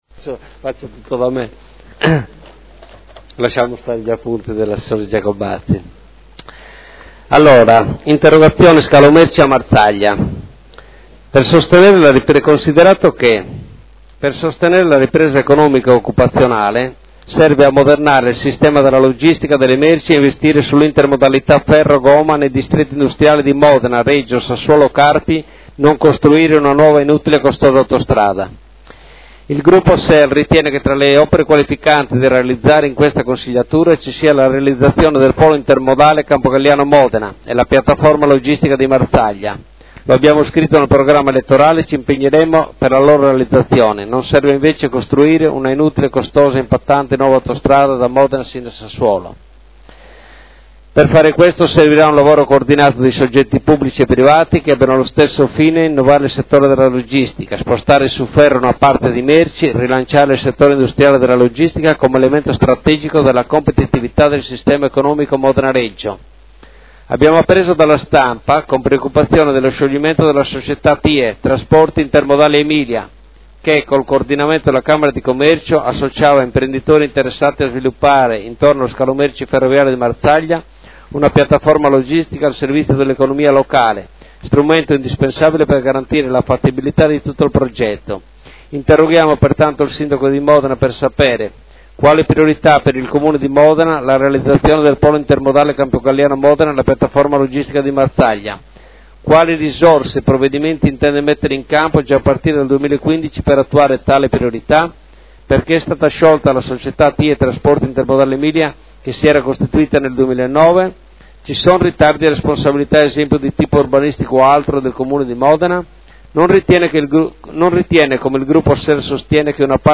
Seduta del 09/07/2015 Interrogazione del consigliere Cugusi (SEL) avente per oggetto: “Scalo merci a Marzaglia”